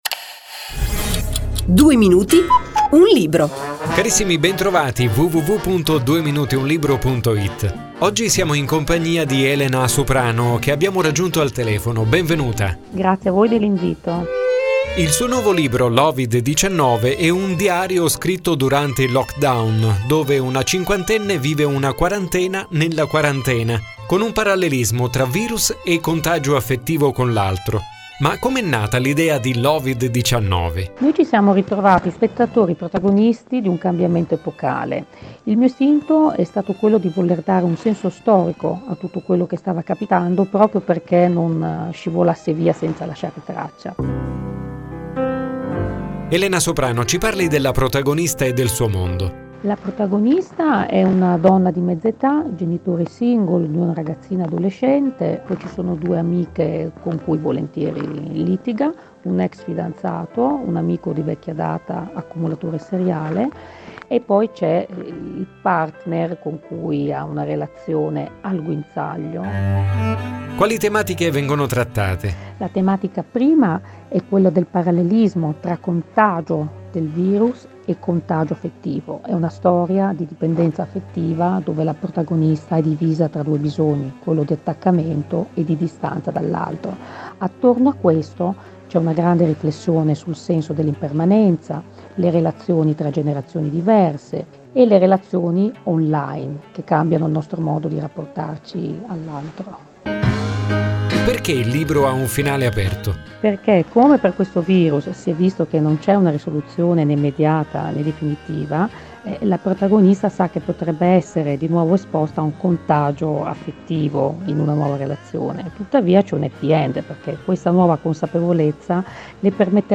🎙 Clip radiofonica del libro